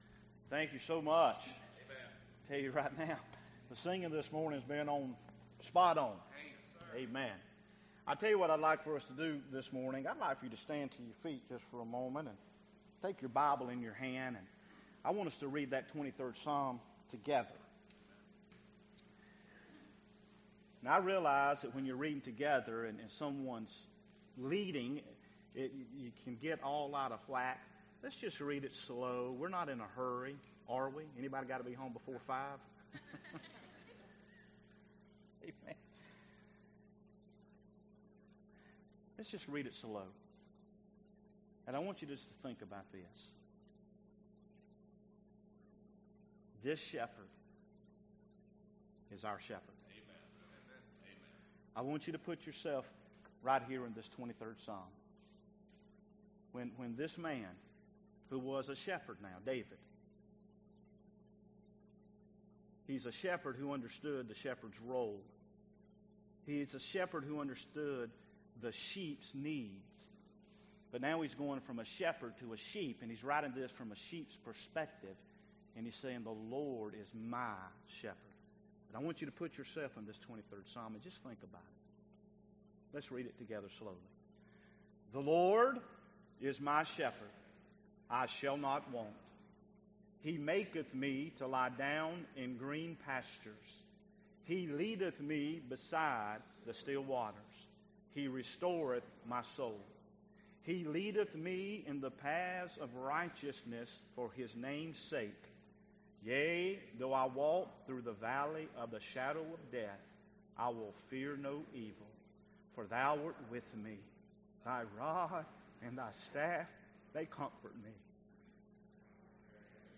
Sermons Archive • Page 2 of 184 • Fellowship Baptist Church - Madison, Virginia